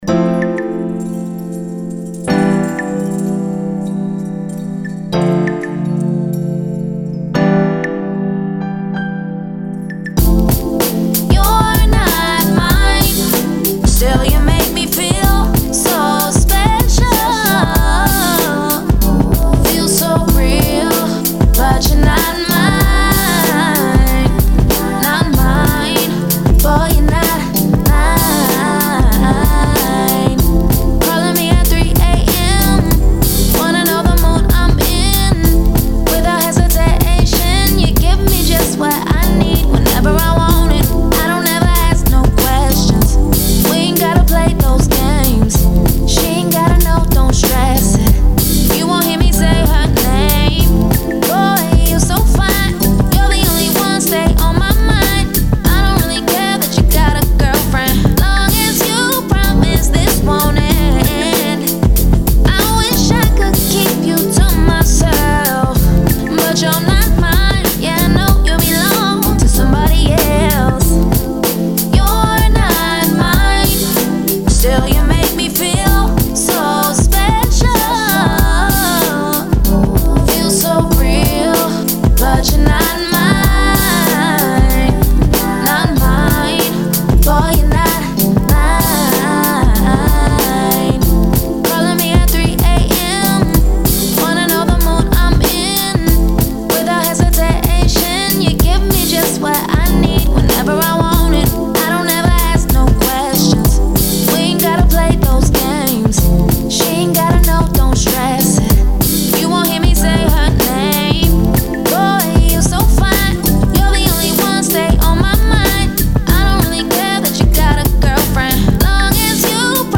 Soul, 90s, R&B
A min